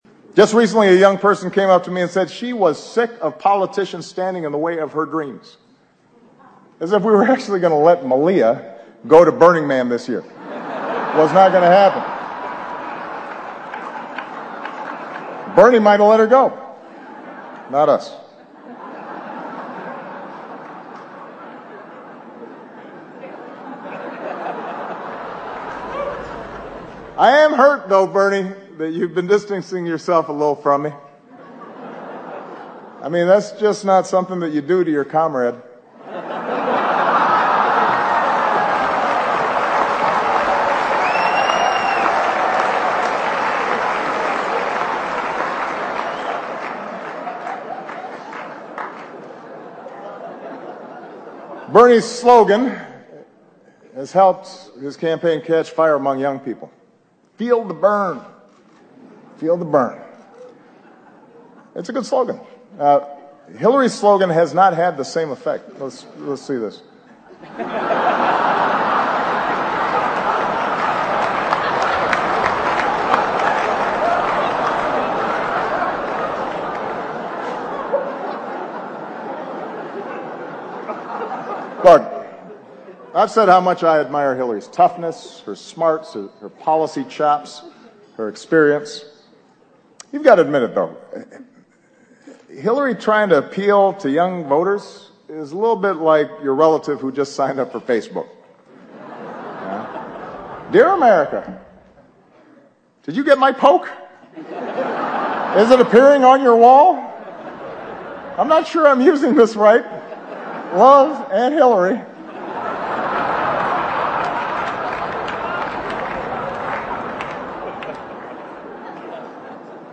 欧美名人演讲 第86期:奥巴马任内末次白宫记者晚宴演讲(8) 听力文件下载—在线英语听力室